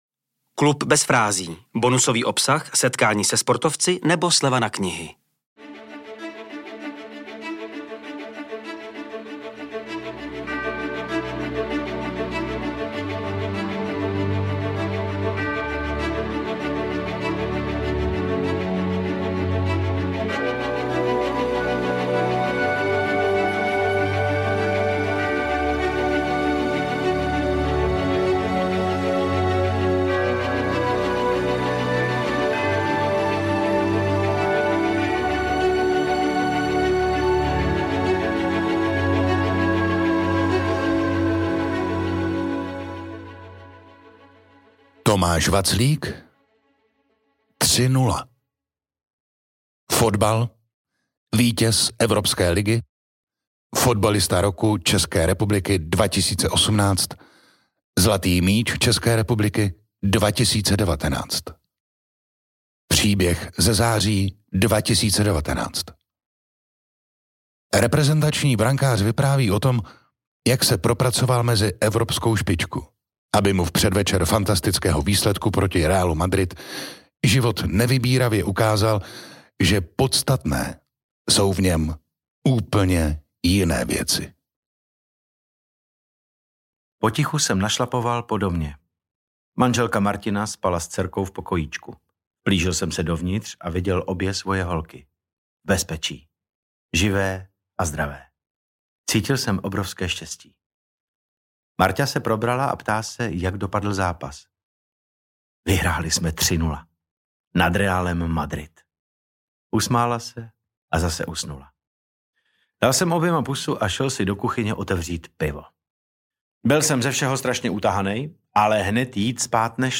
Poslechněte si příběh, který pro vás namluvil skvělý herec David Novotný .